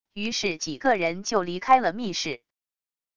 于是几个人就离开了密室wav音频生成系统WAV Audio Player